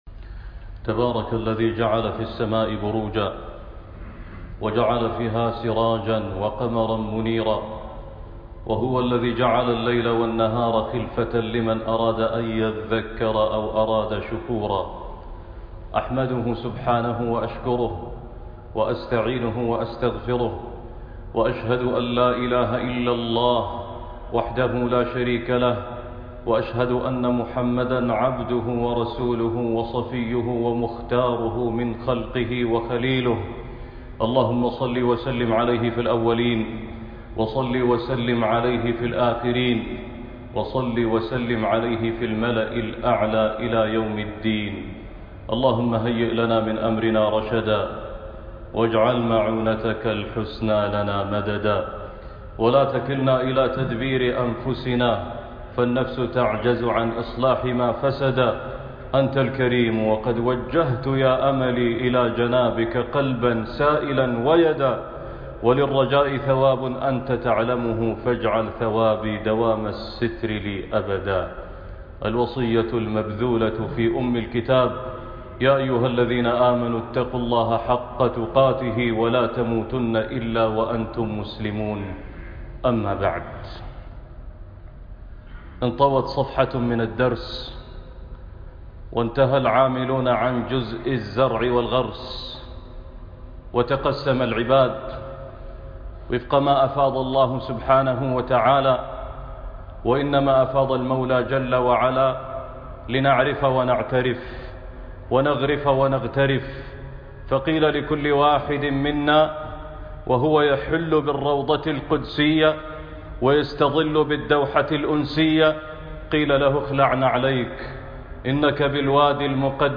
غرس رمضان- خطبة الجمعة